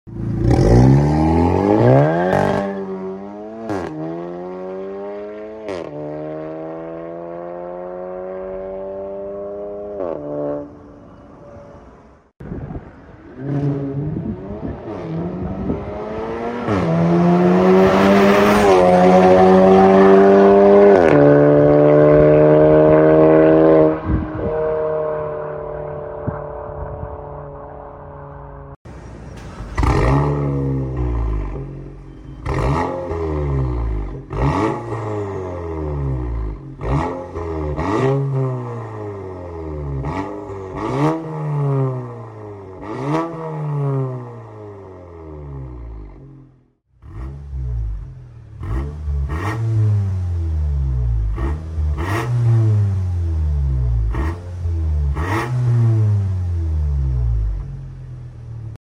320HP VW GOLF GTI PERFORMANCE Sound Effects Free Download